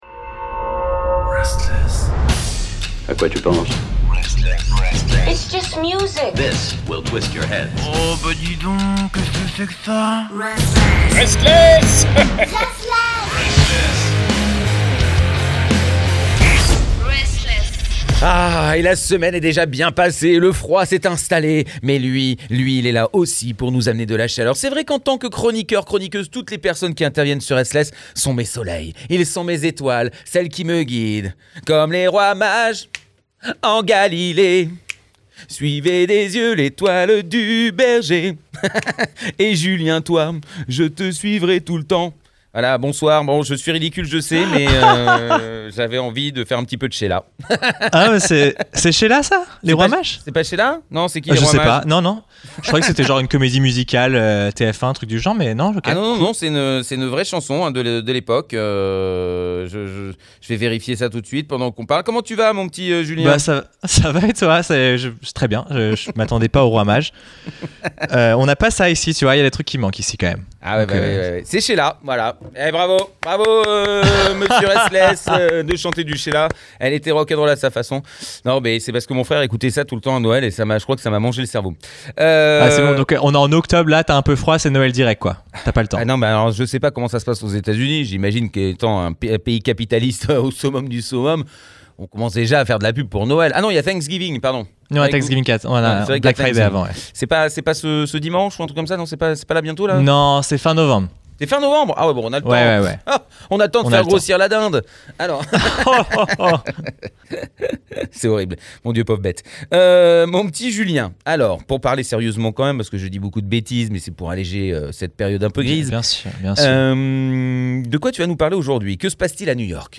On peut dire qu’il y a une vibe art-rock, post-punk, new-wave.
Un côté assez brut, honnête, tout en gardant une certaine hargne. Du punk qui donne envie de danser au lieu de se jeter dessus.